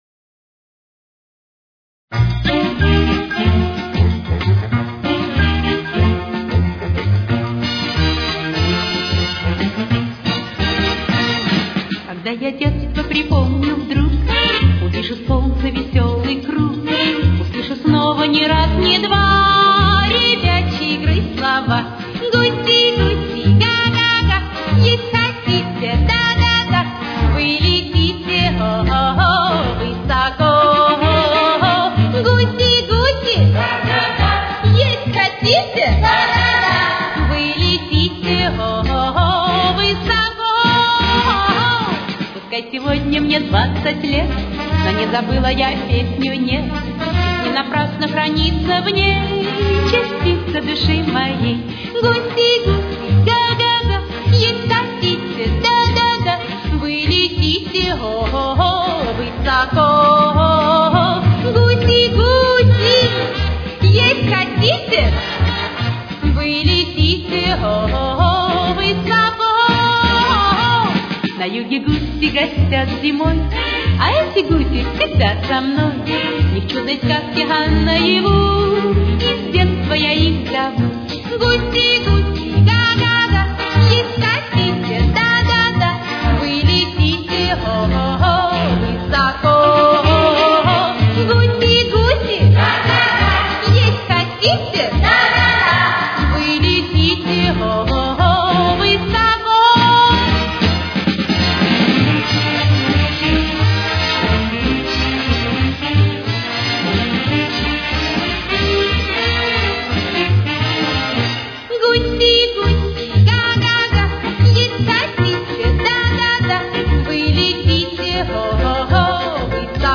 с очень низким качеством (16 – 32 кБит/с)
Си-бемоль мажор. Темп: 195.